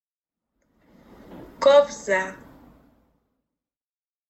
Audio file of the word "Cobza"
Sound-of-the-name-of-the-Cobza.mp3